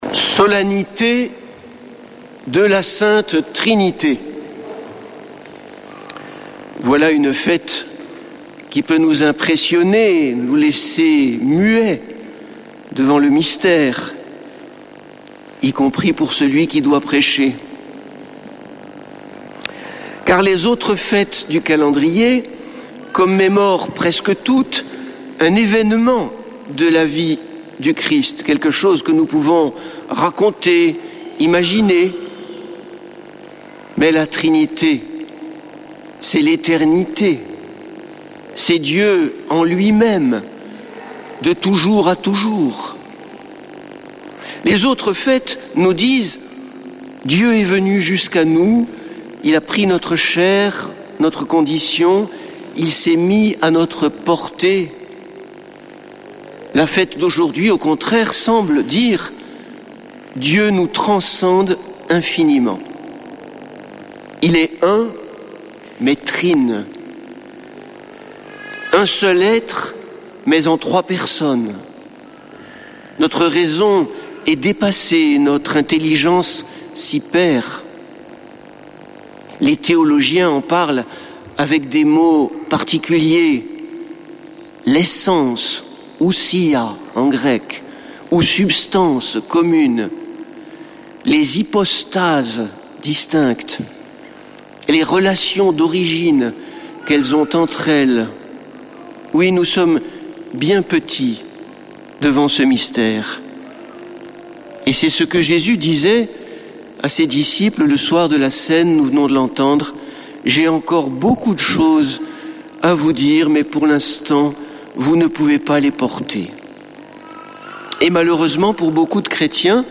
lundi 16 juin 2025 Messe depuis le couvent des Dominicains de Toulouse Durée 01 h 28 min